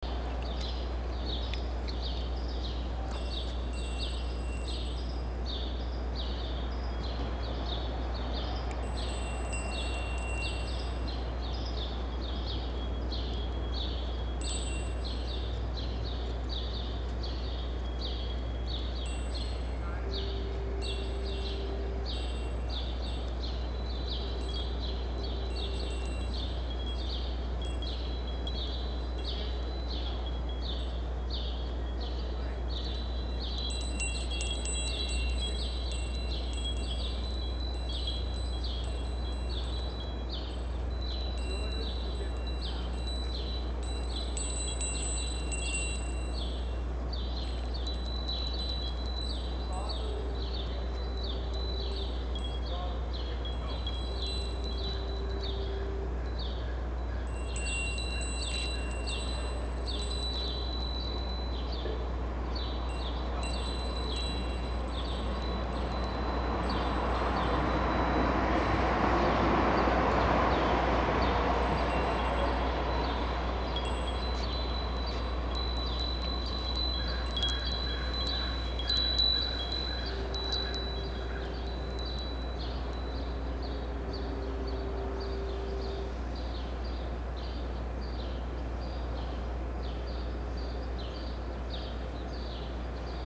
I rang 3 little bells as I walked across the street and back.